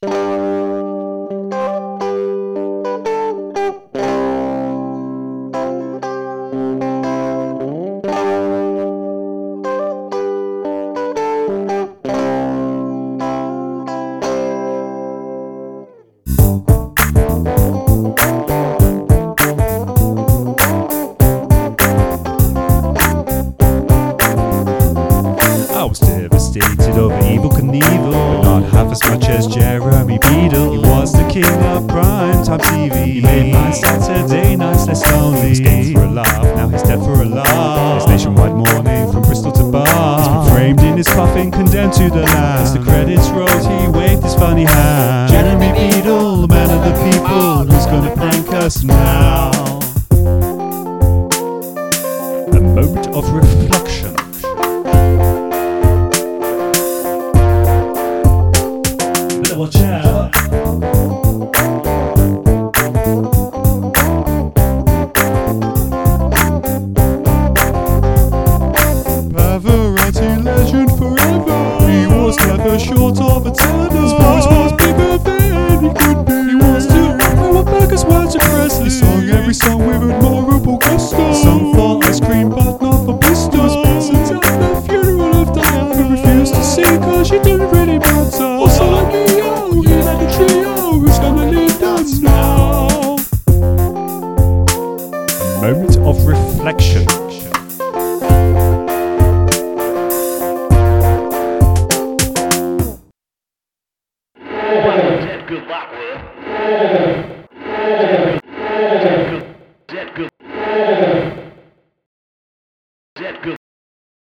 but I always got carried away with the samba-style beat